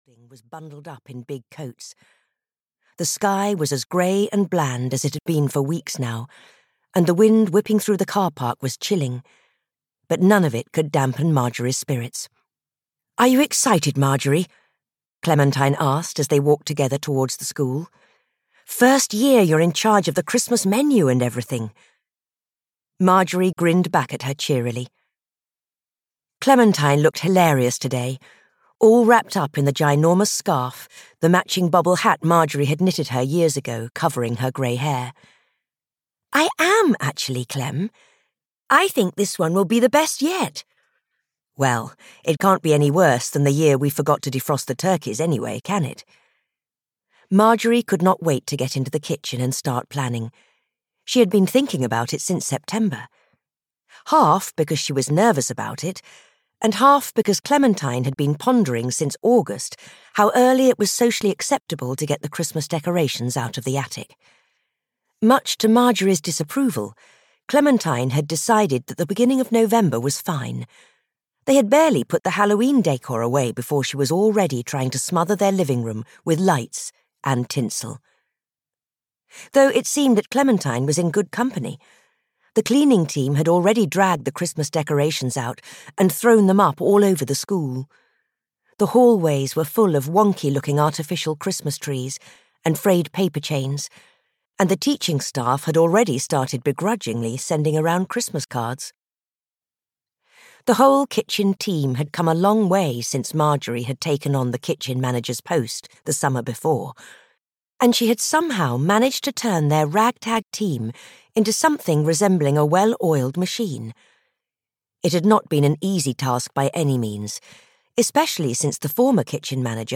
An Unfortunate Christmas Murder (EN) audiokniha
Ukázka z knihy